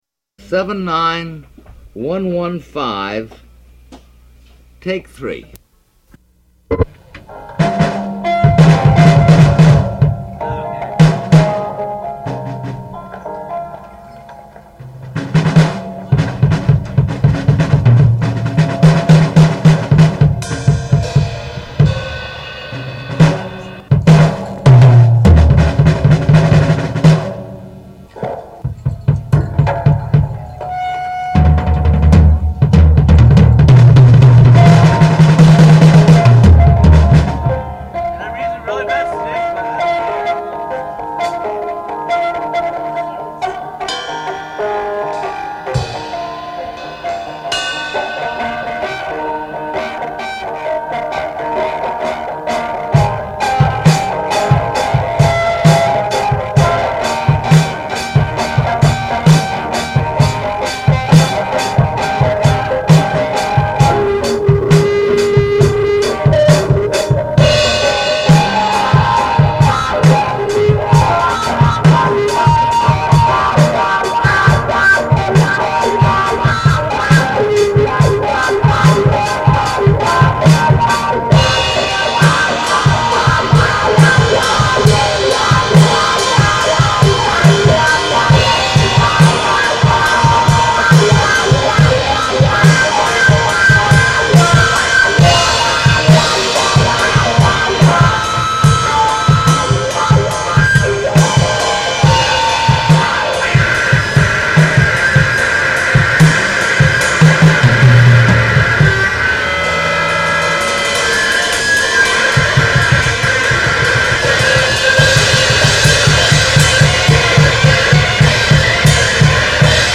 genre: experimental/psychedelic